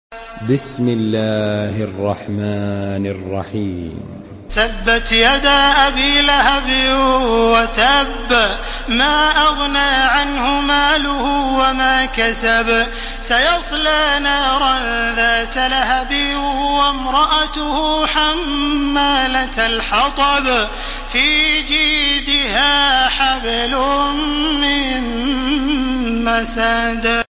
Surah المسد MP3 by تراويح الحرم المكي 1431 in حفص عن عاصم narration.
مرتل